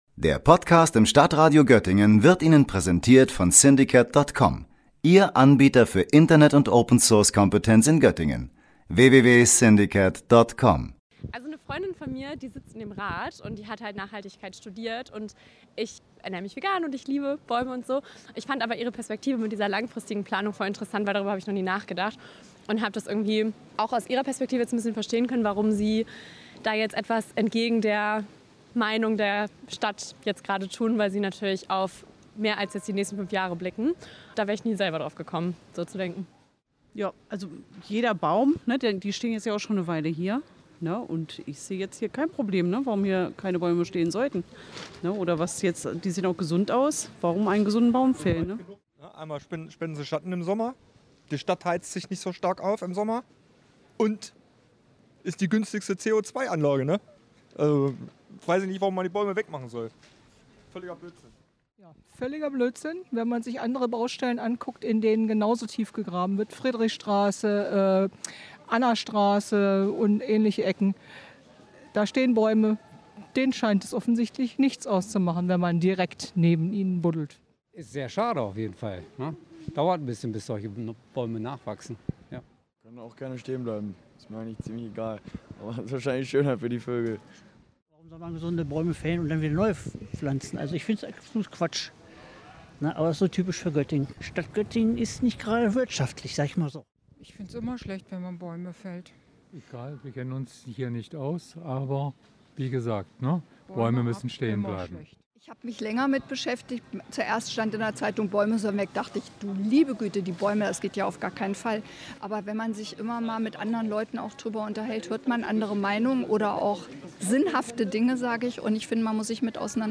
Beiträge > Umfrage in der Weender Straße: Was halten Sie davon, dass die Bäume gefällt werden sollen?